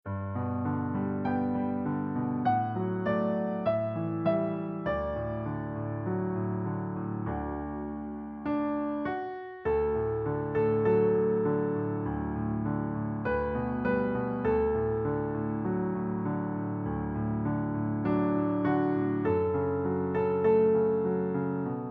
Traditional Song Lyrics and Sound Clip